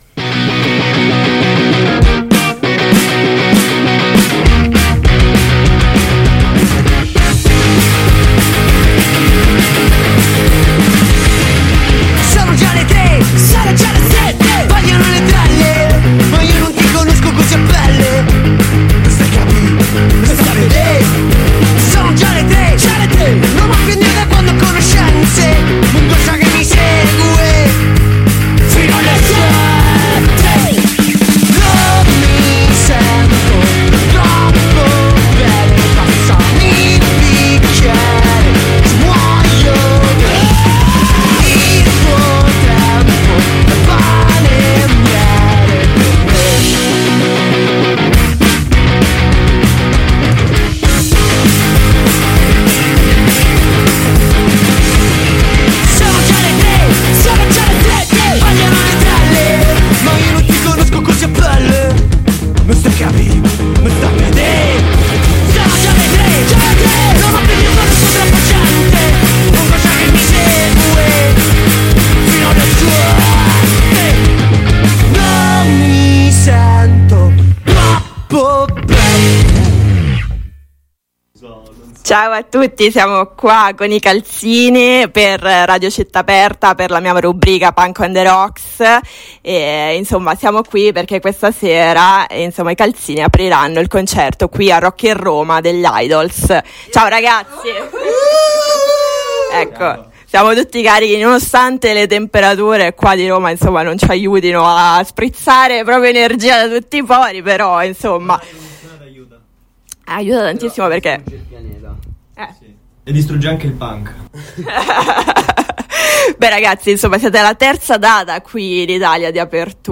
SCALETTA Calzeeni – Gambino – live INTERVISTA AI CALZEENI
intervista-calzeeni-25-7-22.mp3